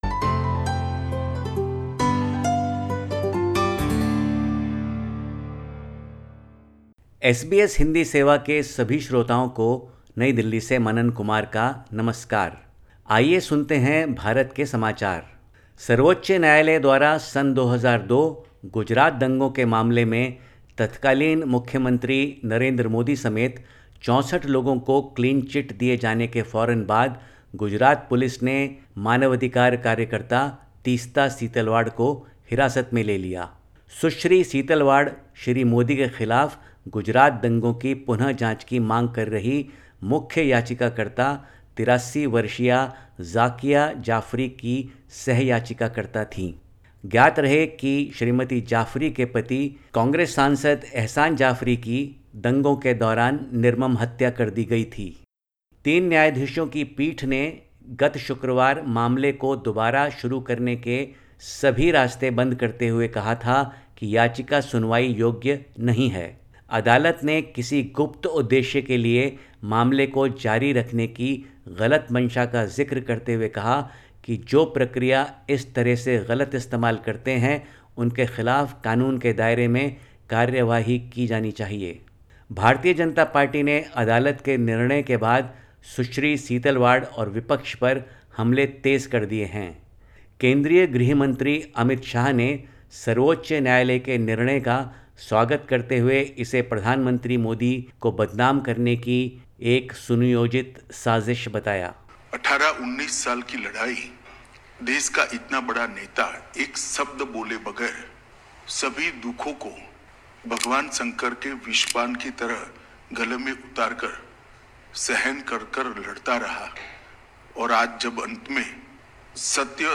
Listen to the latest SBS Hindi report from India. 27/06/2022